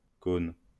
Caulnes (French pronunciation: [kon]